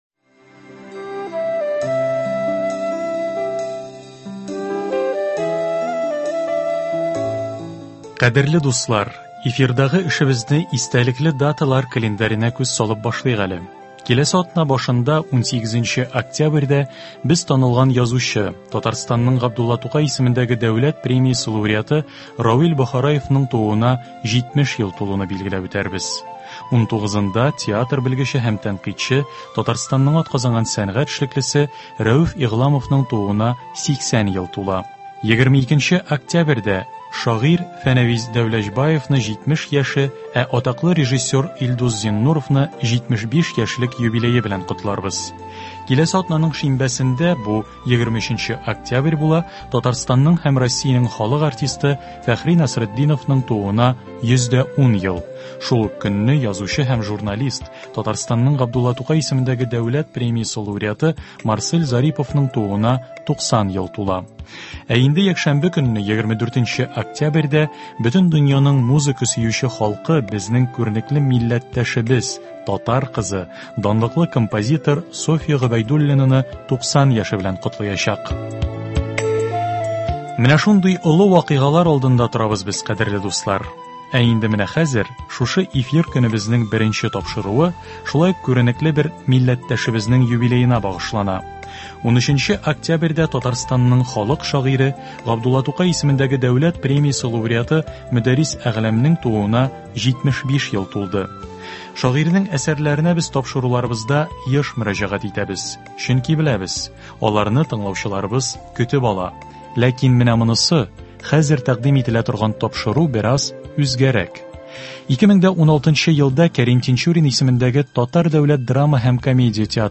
Мөдәррис Әгъләм әсәрләреннән әдәби-музыкаль композиция.